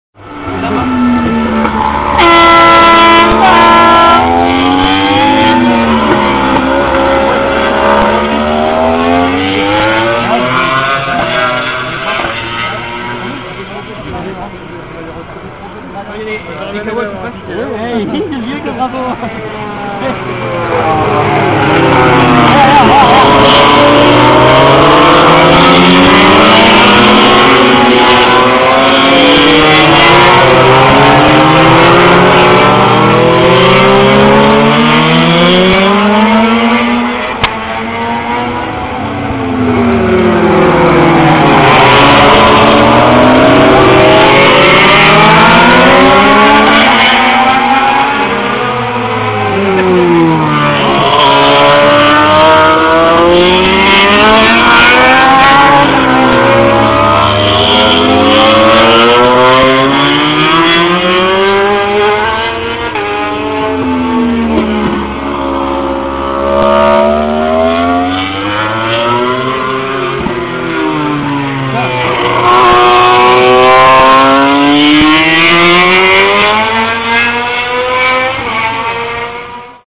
J'en profite pour faire des prises de sons afin d'enregistrer ce bruit rageur que dégagent les MotoGP.
(Lorsqu'on entend le coup de trompe, c'est qu'il s'agit soit de